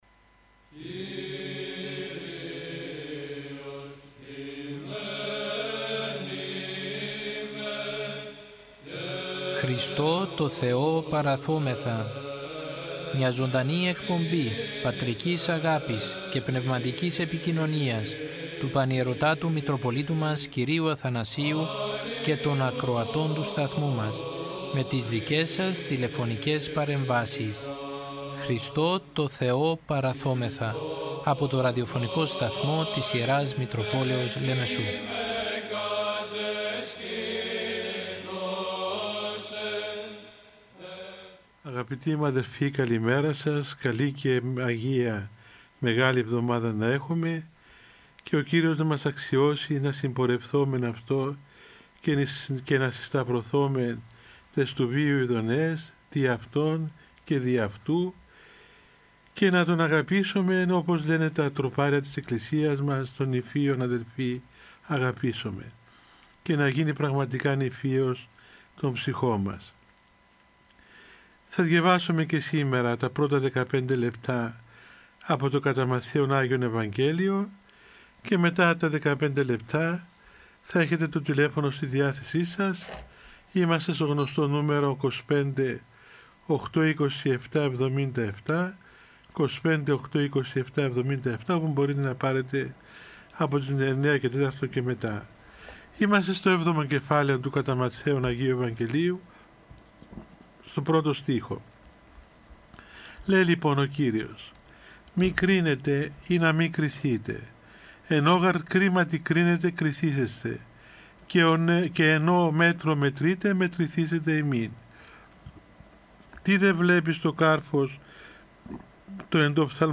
Ο Πανιερώτατος Μητροπολίτης Λεμεσού κ. Αθανάσιος μέσω του ραδιοφωνικού σταθμού της Μητροπόλεως του καθημερινά επικοινωνεί με το ποίμνιο με τηλεφωνικές συνδέσεις και απευθύνει παρηγορητικό λόγο για την δοκιμασία που περνάει ο Ορθόδοξος λαός για την πανδημία με κλειστές εκκλησίες και απαγορεύσεις.